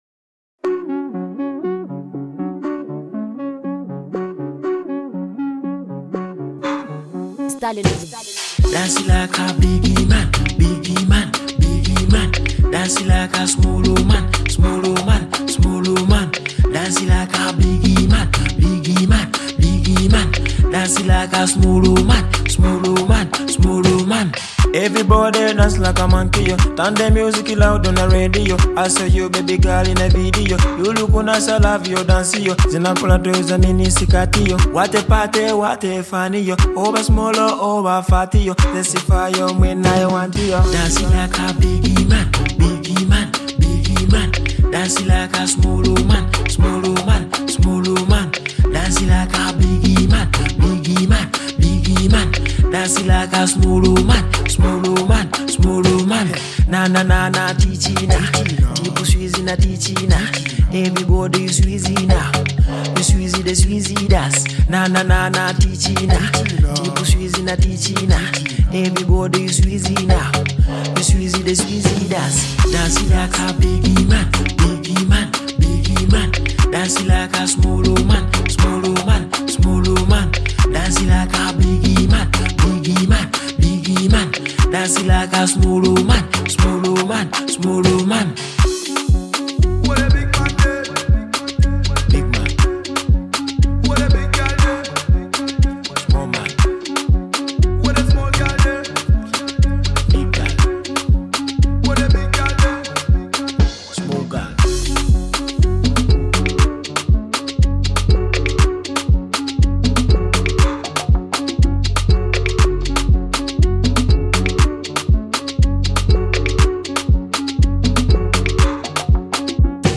Genre: Dance Hall